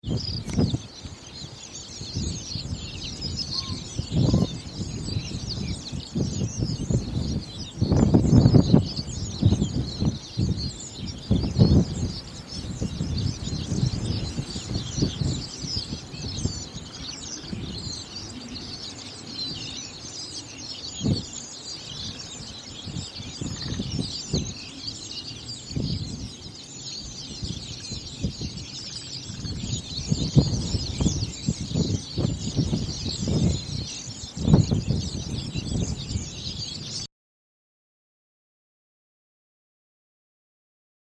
Many were singing, providing a wonderful soundtrack as we scanned through the flocks.  A single male Yellow-breasted Bunting was with the group and it, too, sang on occasion.
A short recording of the cacophony can be heard here:
Little Buntings
little-buntings.mp3